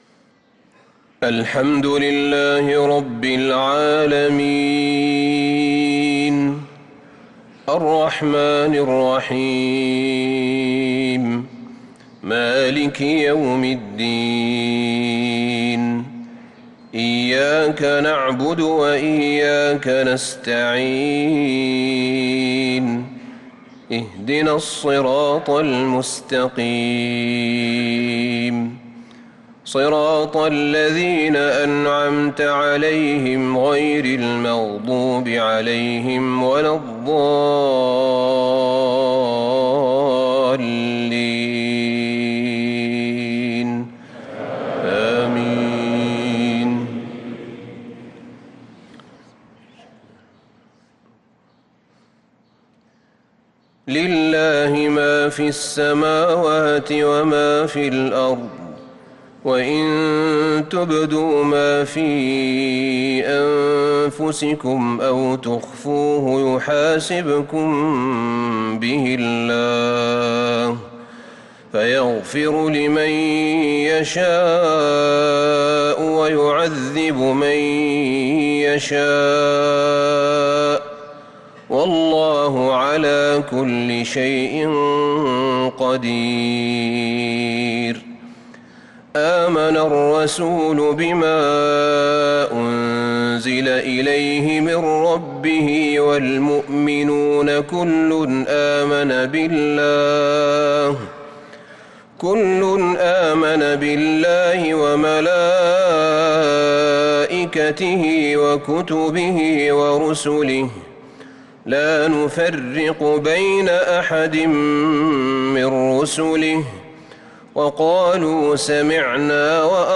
صلاة المغرب للقارئ أحمد بن طالب حميد 19 شعبان 1445 هـ